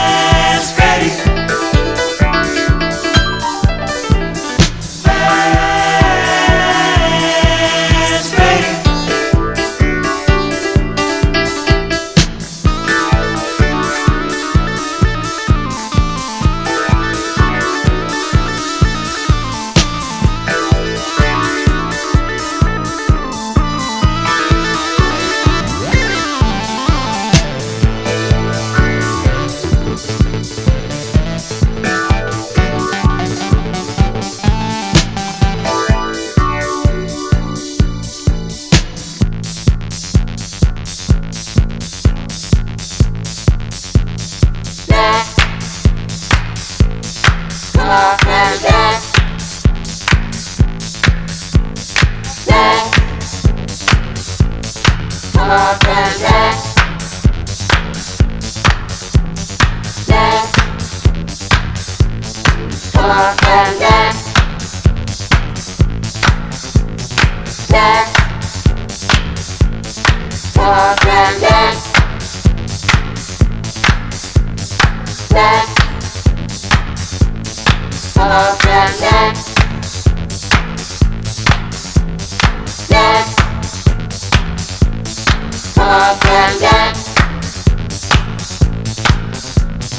温かく爽快なソウル感やスペーシーなムーグ・ワークが交錯するナイスなブギー・チューンです。